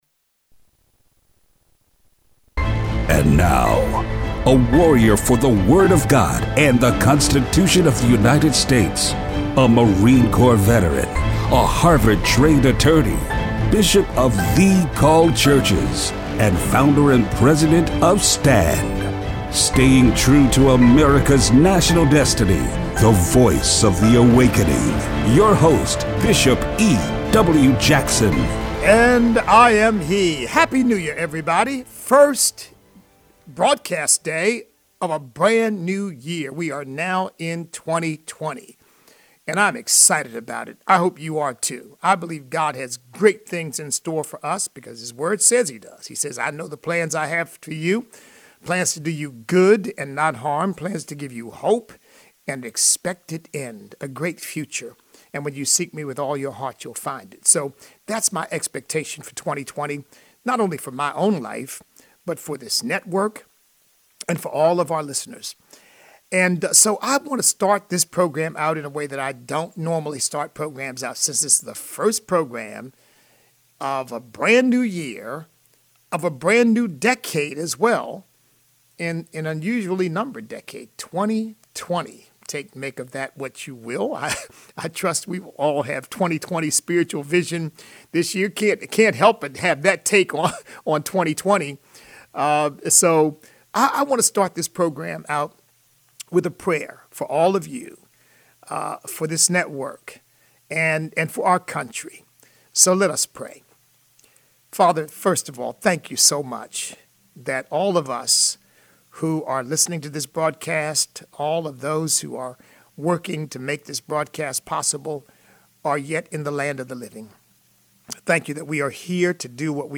Iranian embassy attack. Listener call-in.